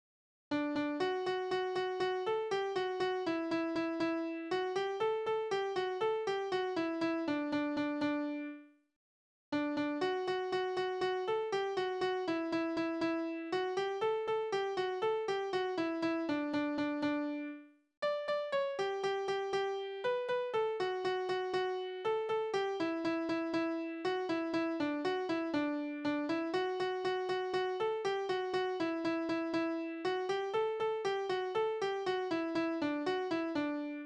Tonart: D-Dur
Taktart: 2/4
Tonumfang: Oktave
Besetzung: vokal